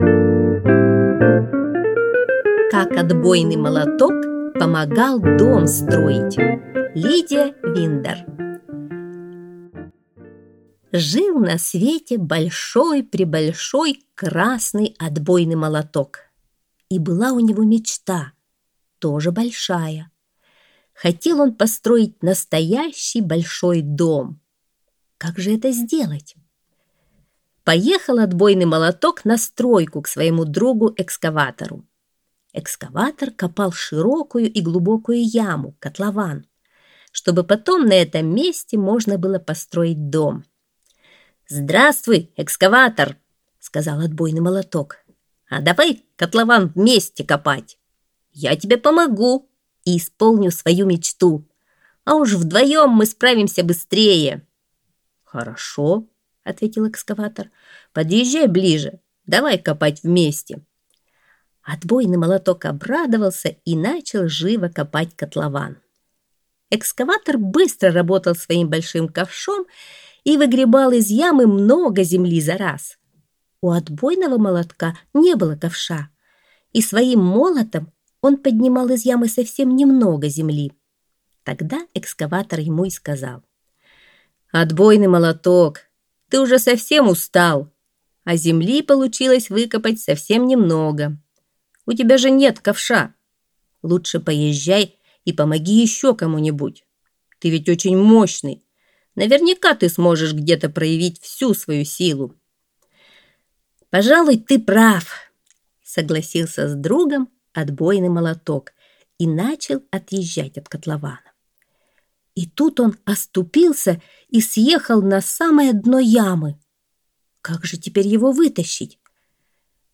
Аудиосказка «Как Отбойный Молоток помогал дом строить»